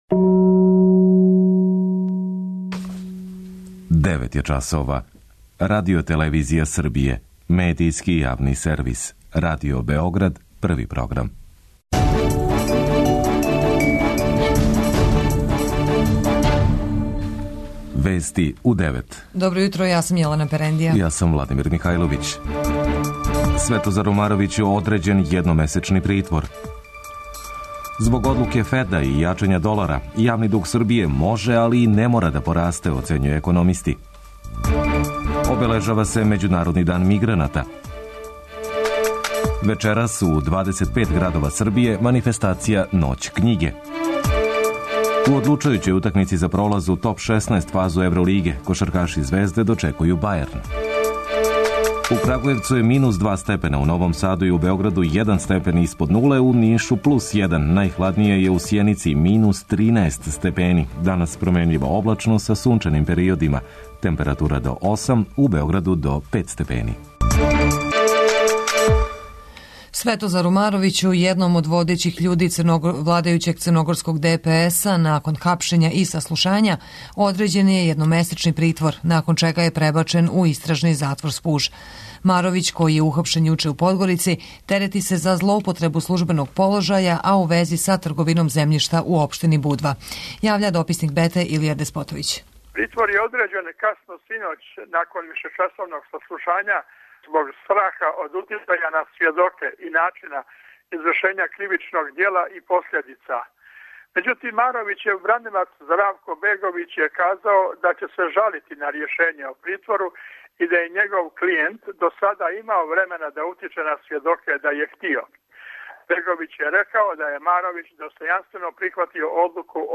преузми : 10.41 MB Вести у 9 Autor: разни аутори Преглед најважнијиx информација из земље из света.